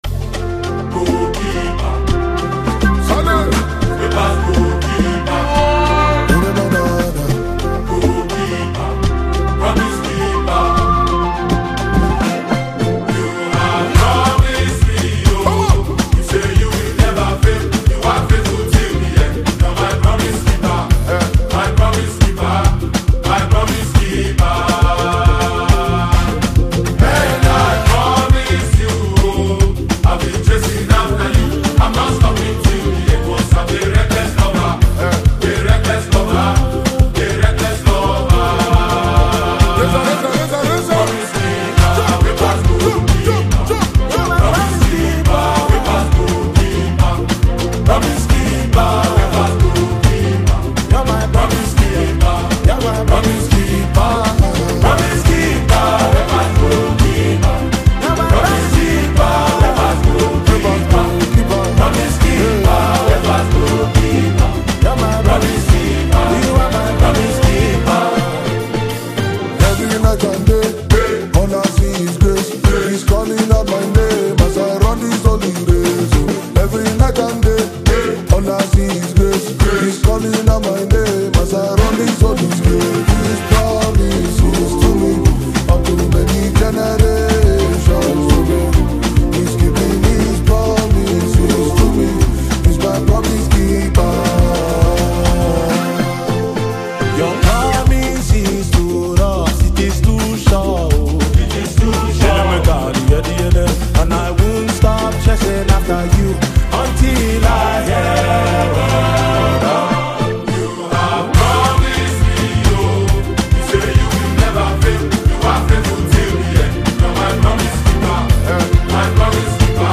a powerful worship song
Gospel Songs